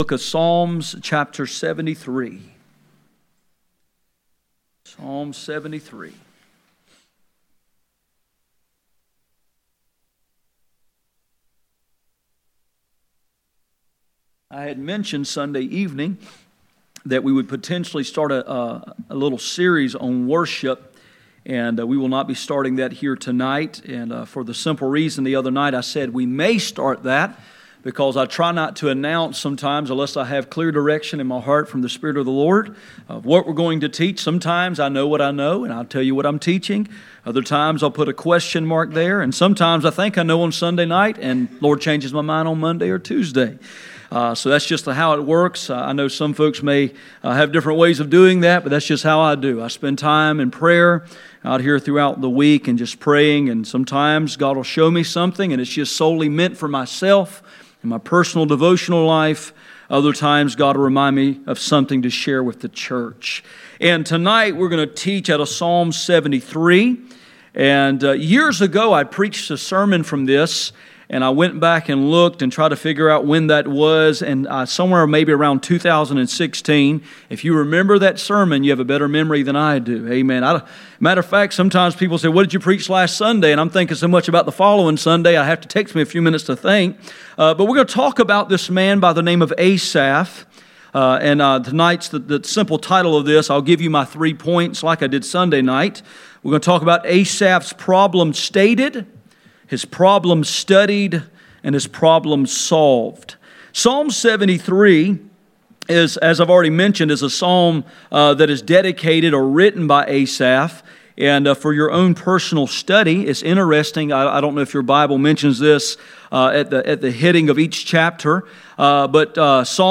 None Passage: Psalm 73:1-28 Service Type: Midweek Meeting %todo_render% « Pick up